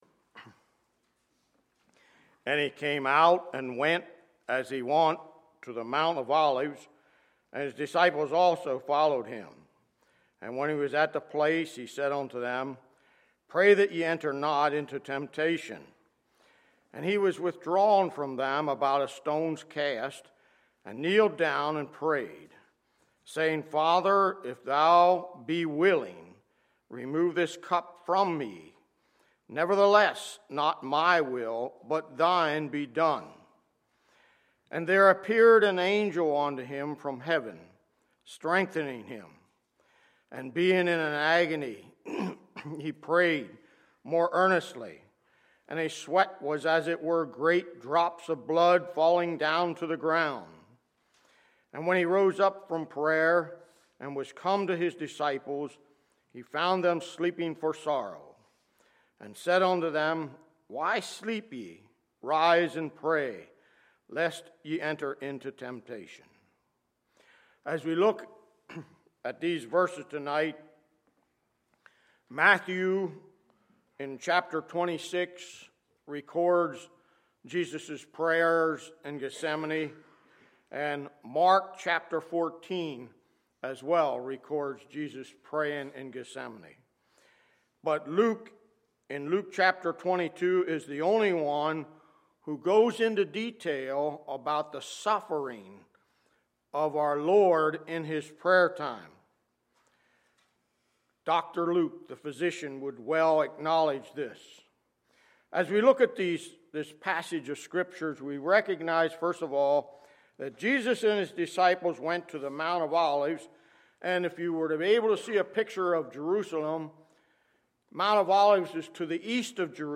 Friday, April 3, 2015 – Friday Evening Service